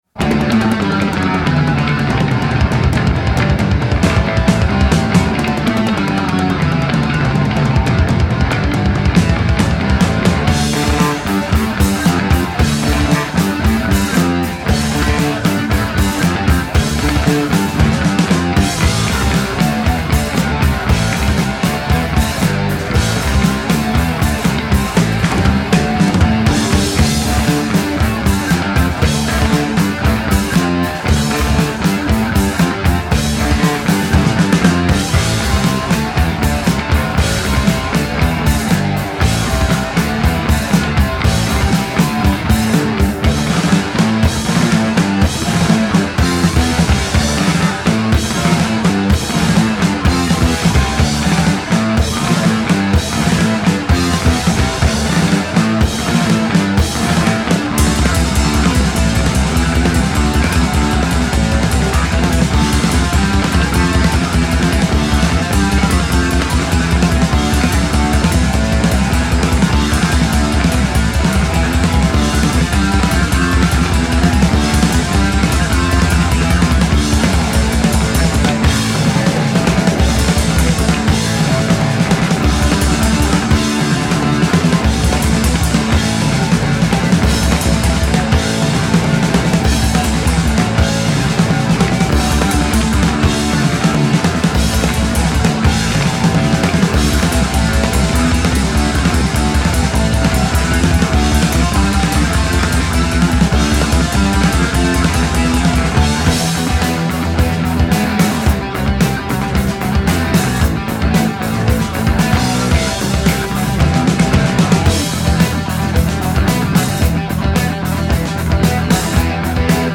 Dans ce genre de la « New Wave Of British Heavy Metal«